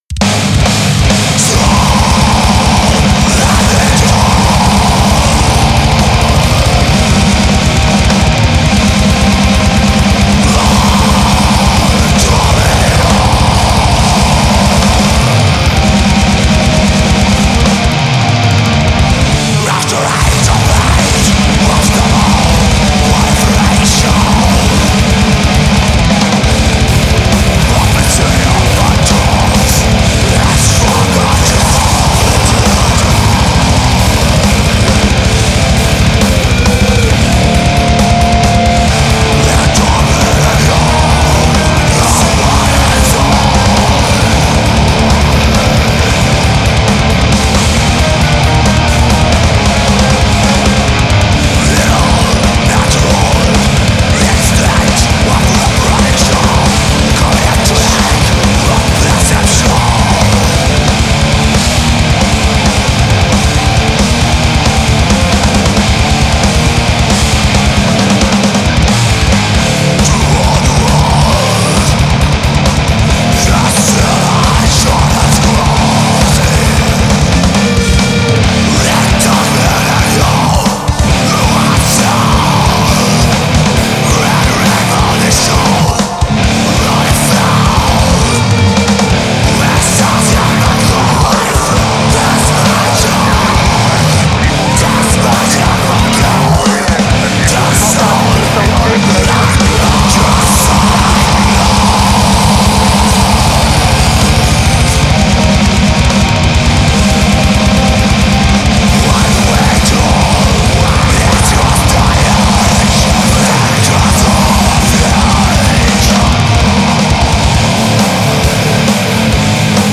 Black - France
Produit au Hybreed Studio
d'un Black Metal lourd, intense et sombre.